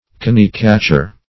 Search Result for " cony-catcher" : The Collaborative International Dictionary of English v.0.48: Cony-catcher \Co"ny-catch`er\, n. A cheat; a sharper; a deceiver.